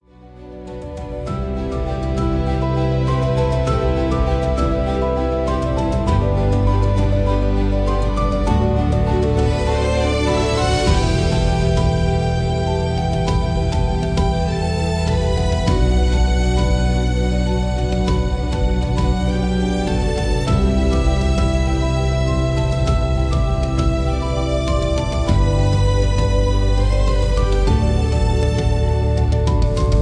Cinematic hybrid instrumental music.